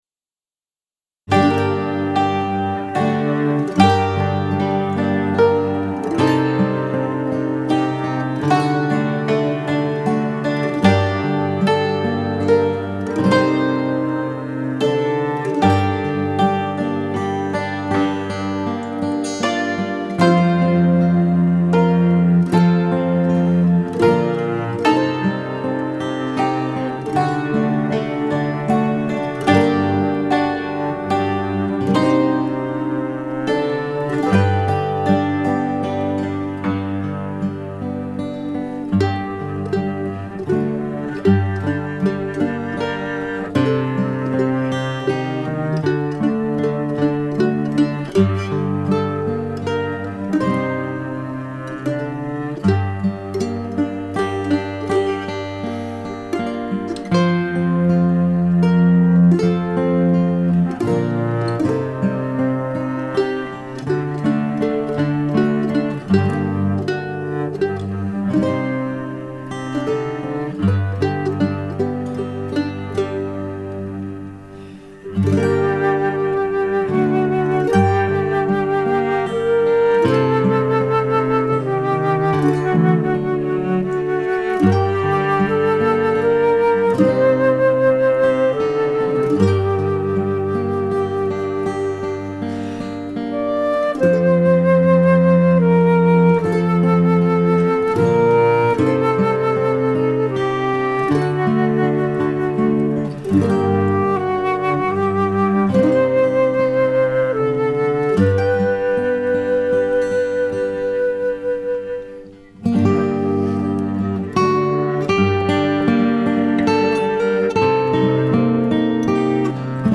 DIGITAL SHEET MUSIC - HAMMERED DULCIMER SOLO
Traditional English Christmas Carol